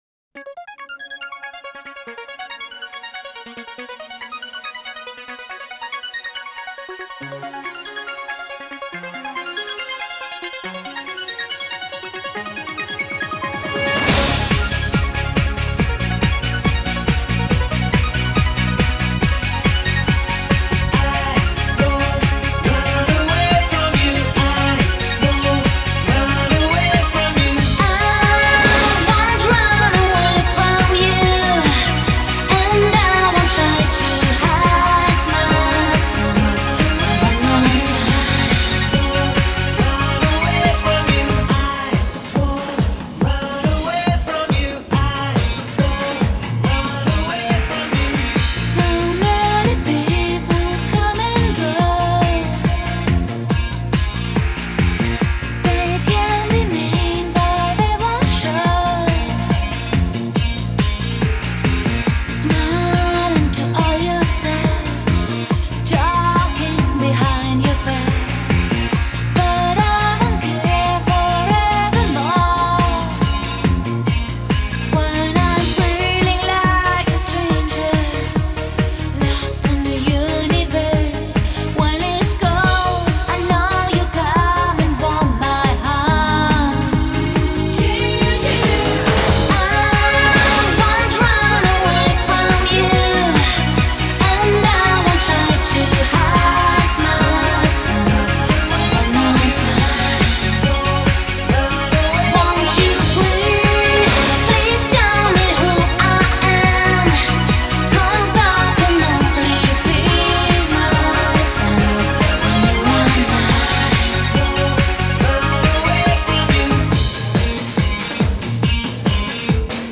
Жанры: синти-поп, танцевальная музыка,
евродиско, поп-рок, евродэнс